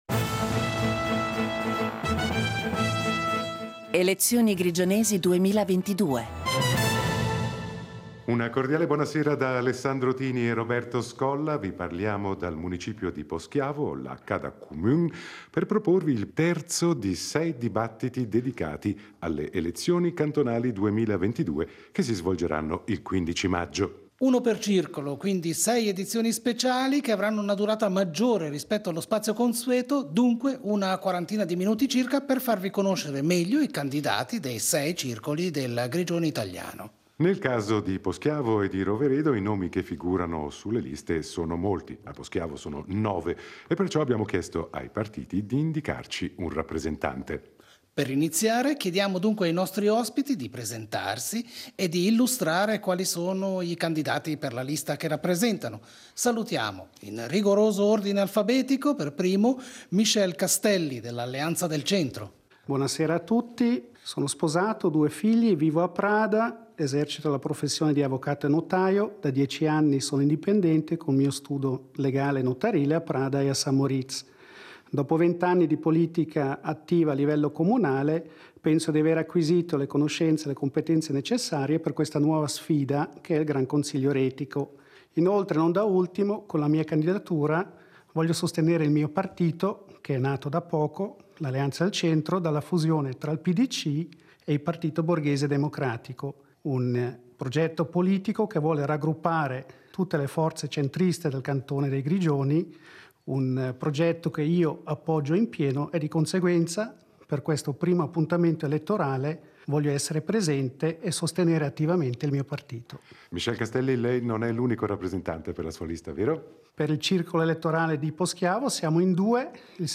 Dibattito in vista delle elezioni retiche del 15 maggio 2022